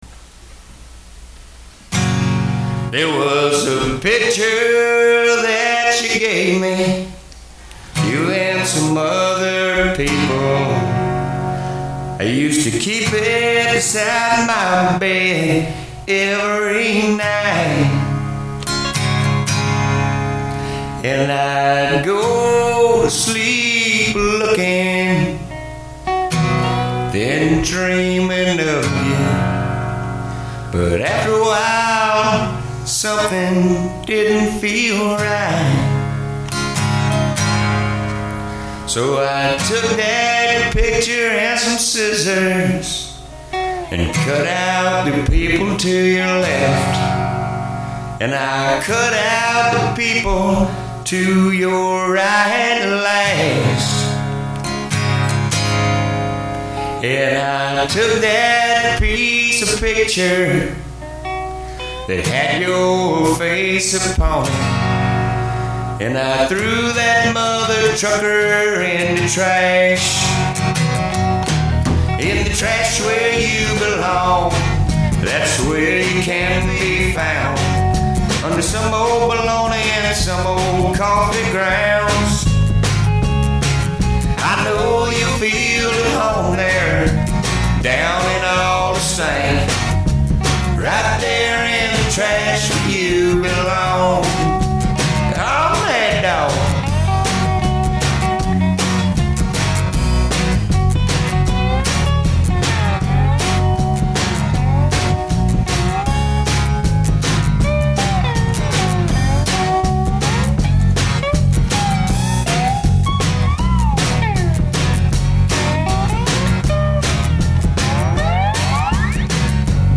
2013 Original With Dobro
Country
Folk
Country-rock